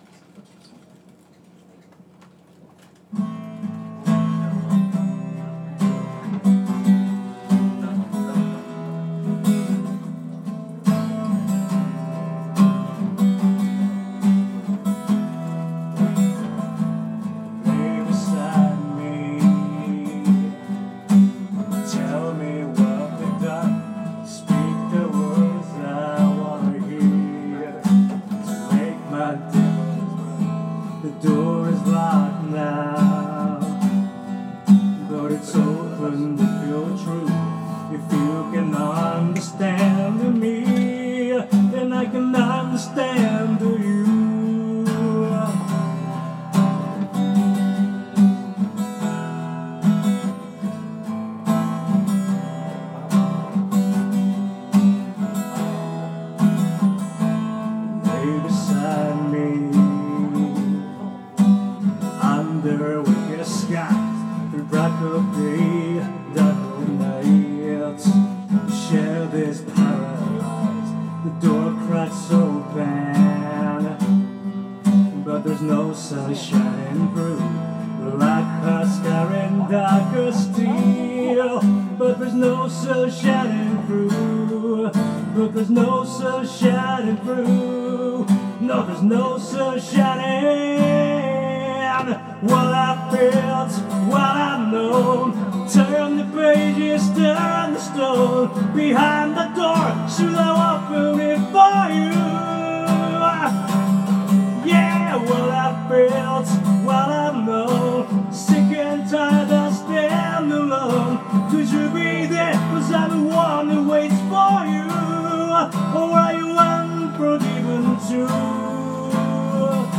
vo gt